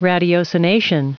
Prononciation du mot ratiocination en anglais (fichier audio)
Prononciation du mot : ratiocination